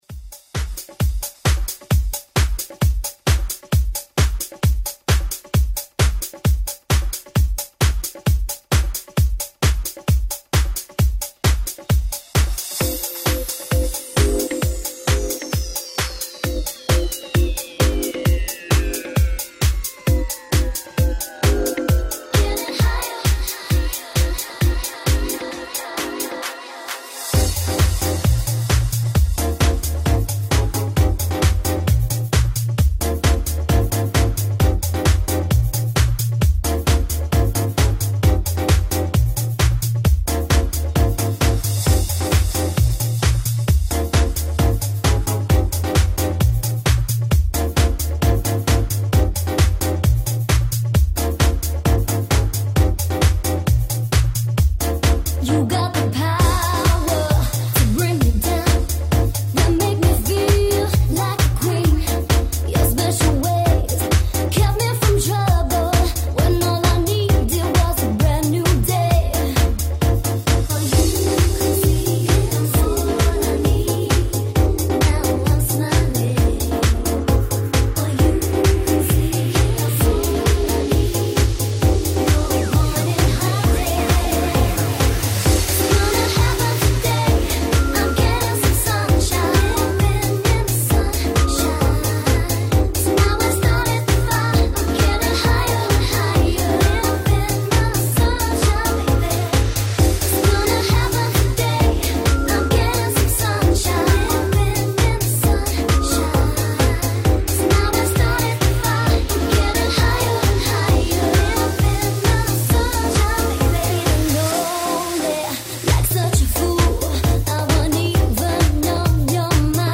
Deep Funk Prog Uplifting.